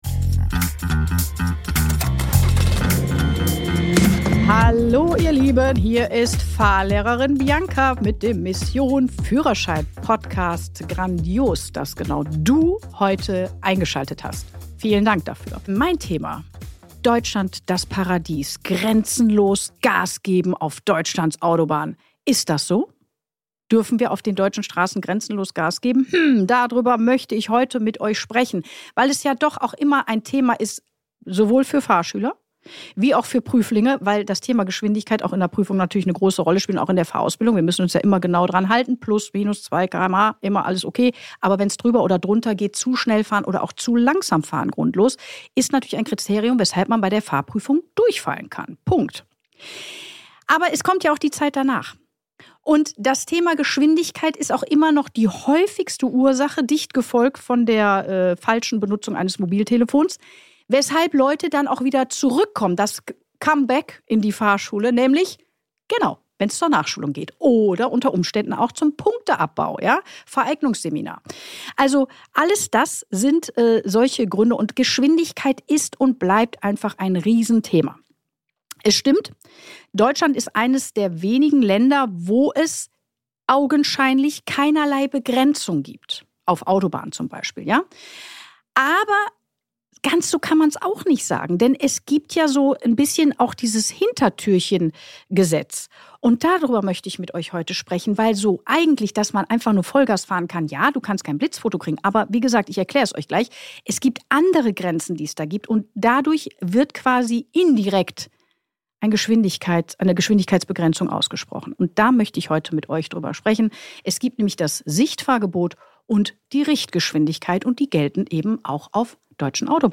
In der neuesten Solo-Episode von Mission Führerschein spreche ich über ein heiß diskutiertes Thema: "grenzenloses Gas geben" auf deutschen Autobahnen.